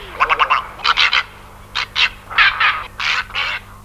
Aigrette garzette
Egretta garzetta
garzette.mp3